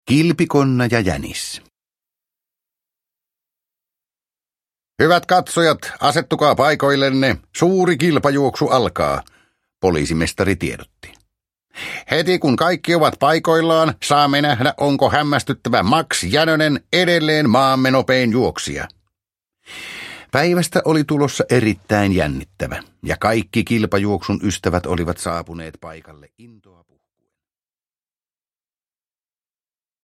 Kilpikonna ja jänis – Ljudbok – Laddas ner